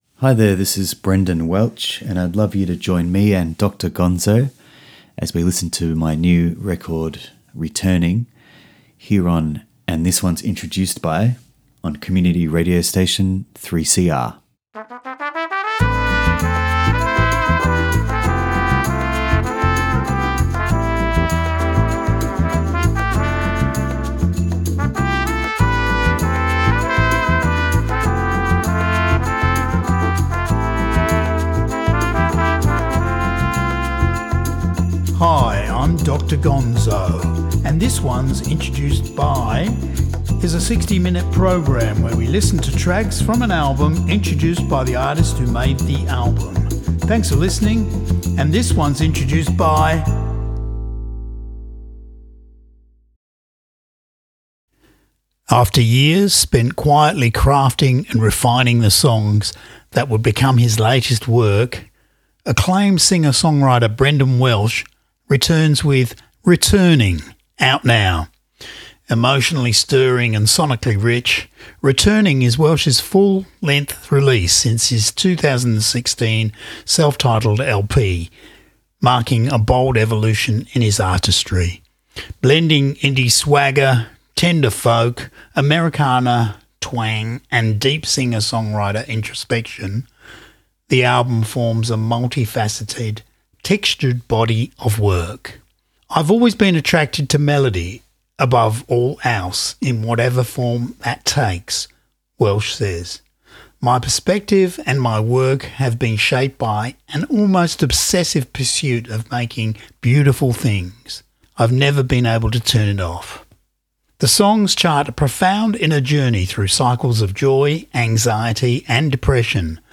multi-instrumentalist
artrock